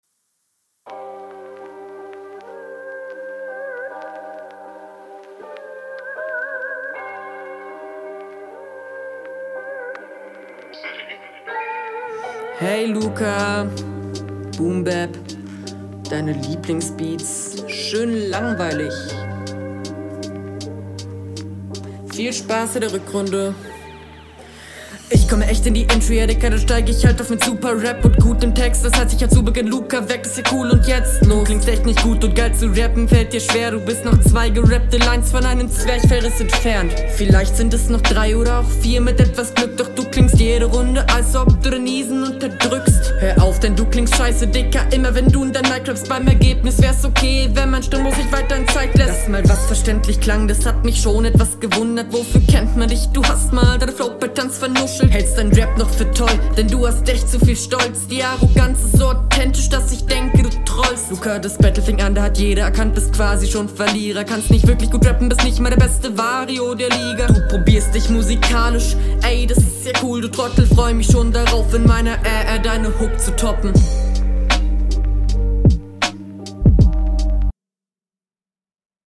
Delivery unverständlich zu beginn.
Sound gefällt mir hier besser als bei deinem Konter. wirkst auch routinierter auf dem Beat.